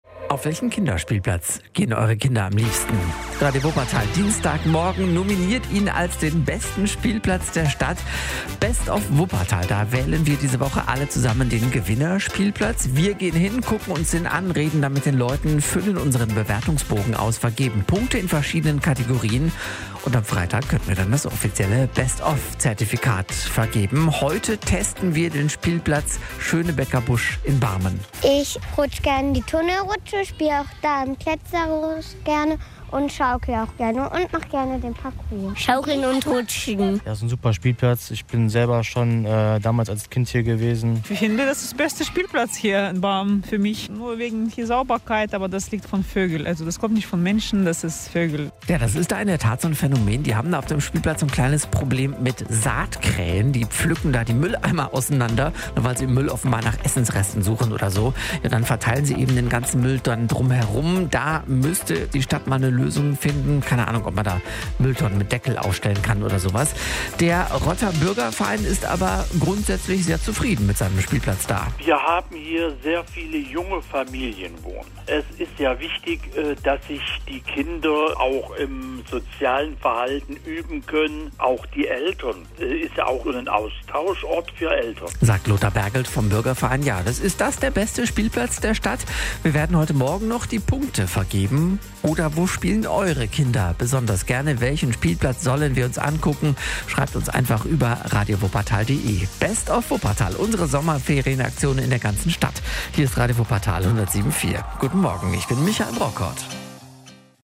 Spielplatz Schönebecker Busch - Umfrage